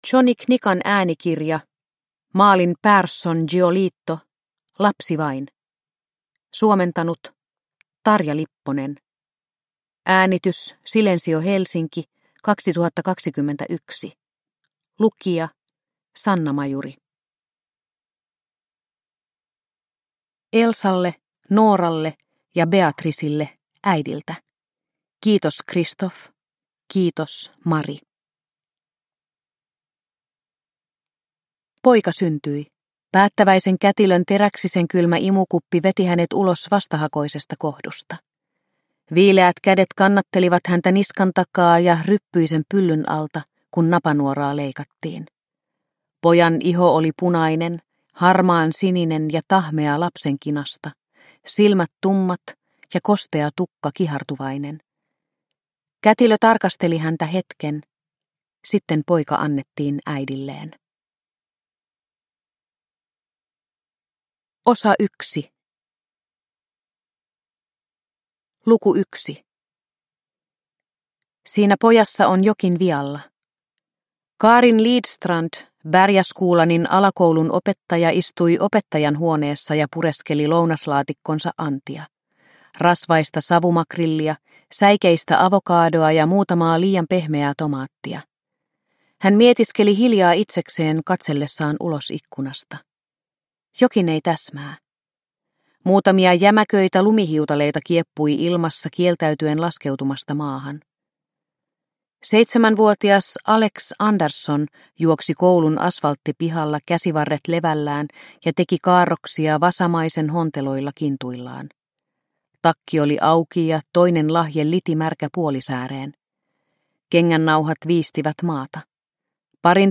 Lapsi vain – Ljudbok – Laddas ner